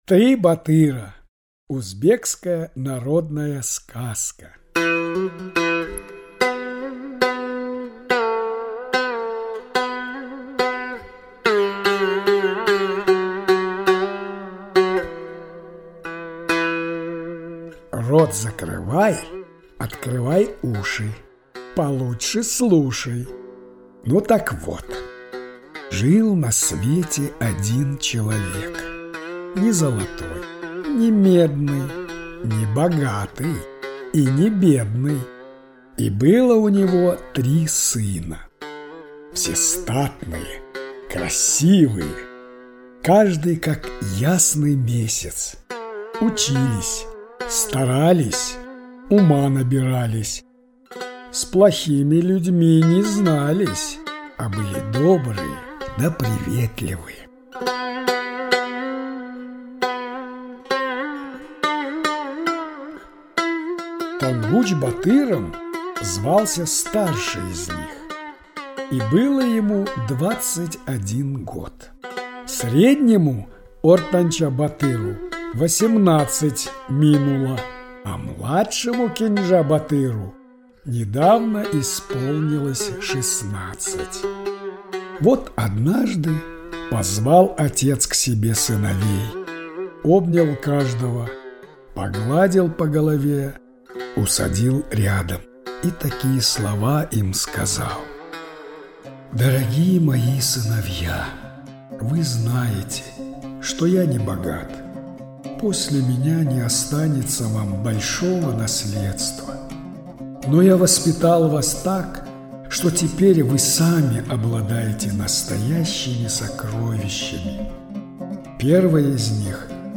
Три батыра - узбекская аудиосказка - слушать онлайн